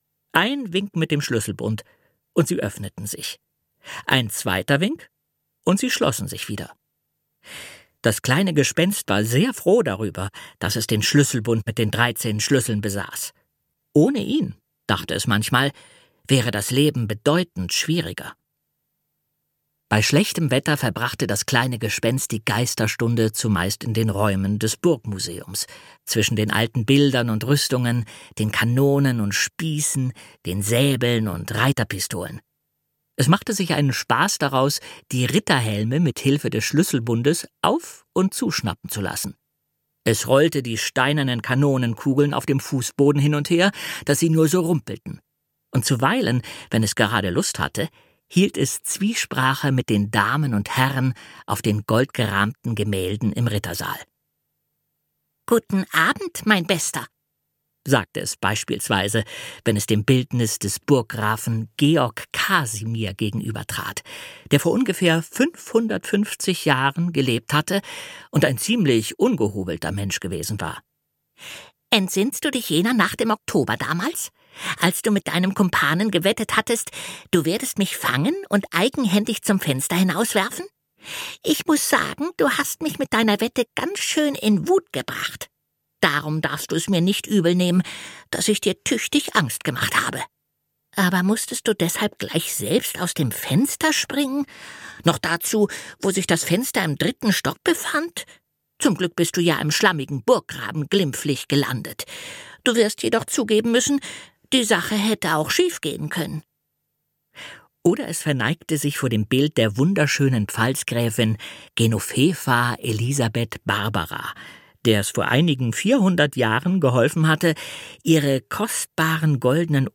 Das kleine Gespenst - Otfried Preußler - Hörbuch